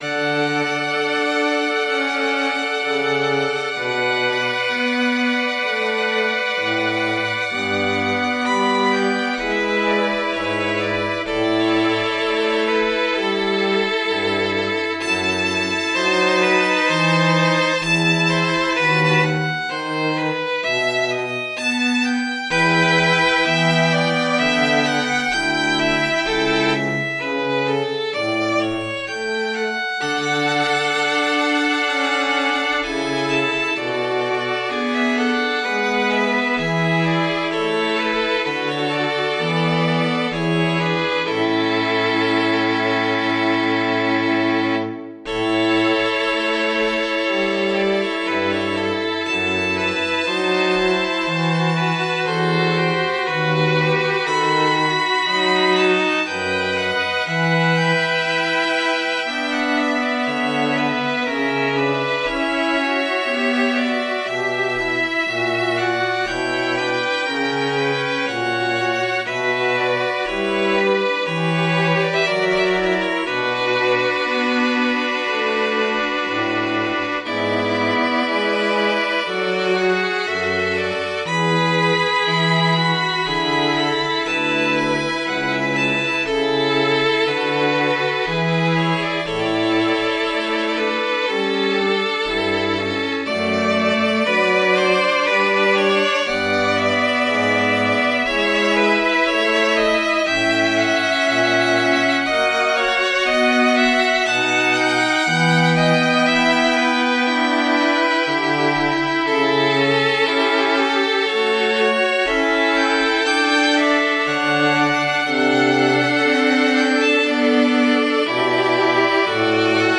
Voicing: 4 Strings